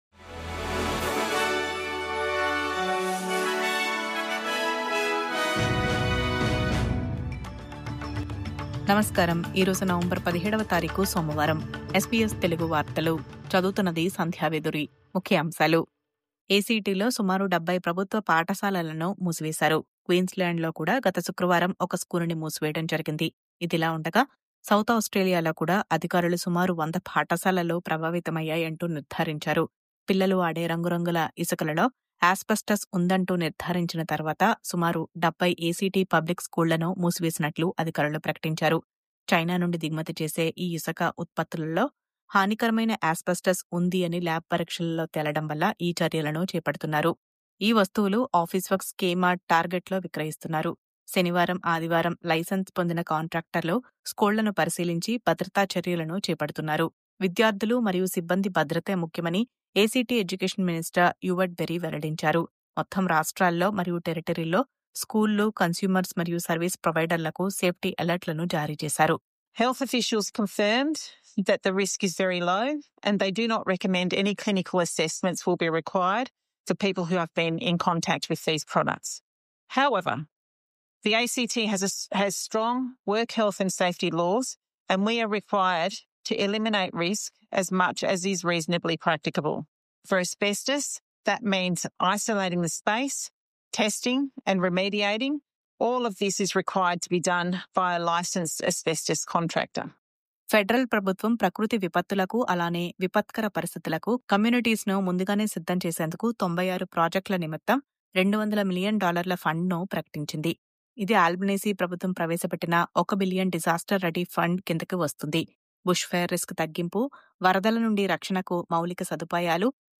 News update: ACTలో 70 పాఠశాలలు మూసివేత… ఇతర రాష్ట్రాల్లో ‘అస్బెస్టాస్’ కలకలం..